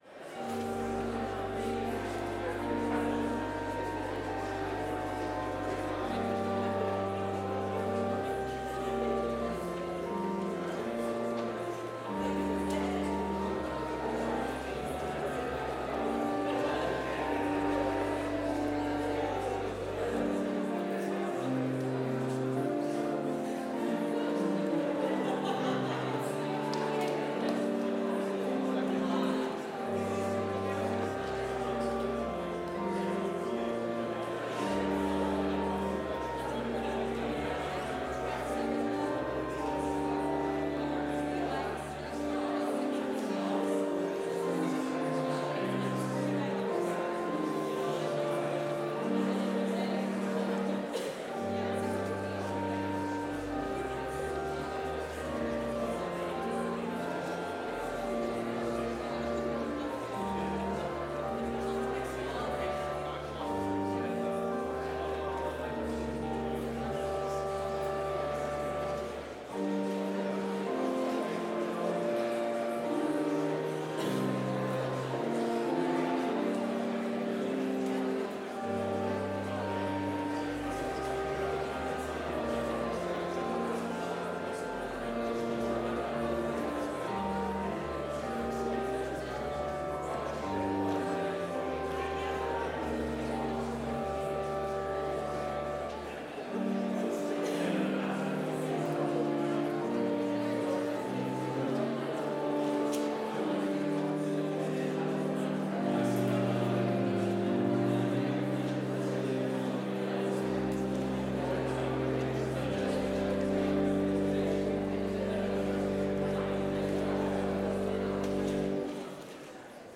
Complete service audio for Chapel - Monday, December 2, 2024